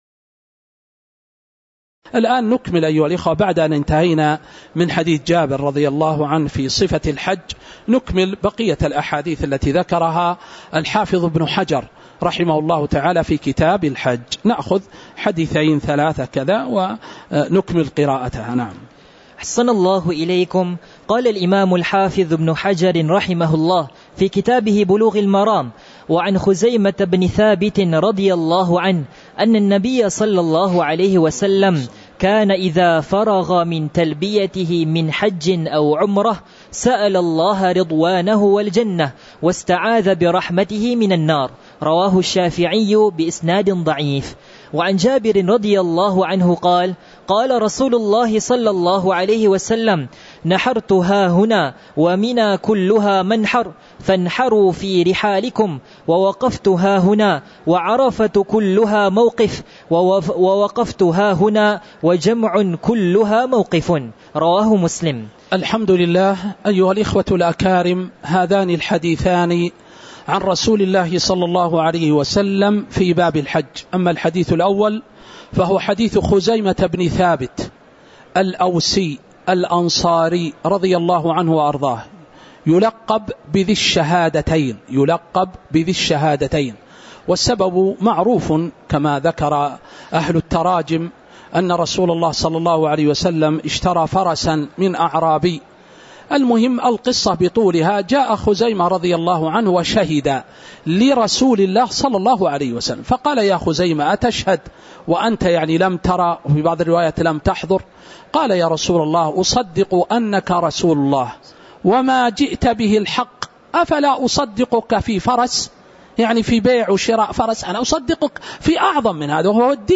تاريخ النشر ٢٧ ذو القعدة ١٤٤٥ هـ المكان: المسجد النبوي الشيخ